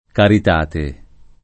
caritate [ karit # te ]